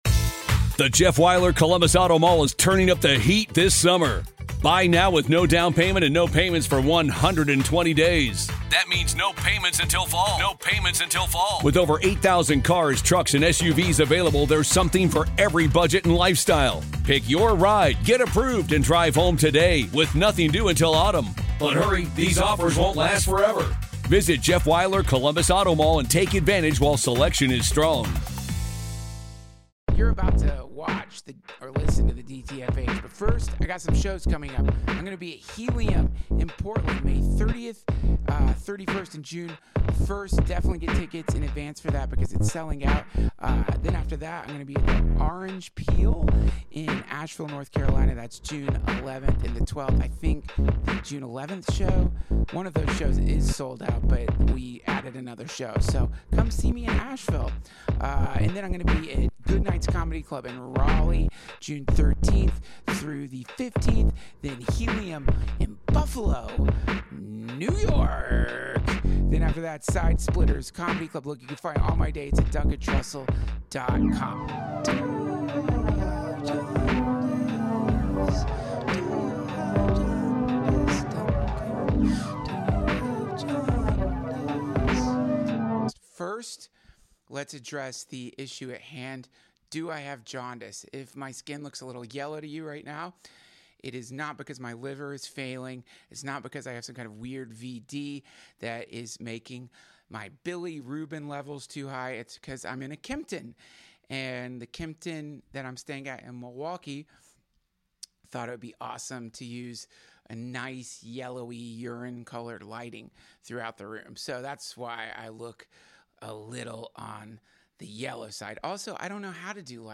Duncan hosts a special road episode of the DTFH! His liver is NOT failing!